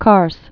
(kärs)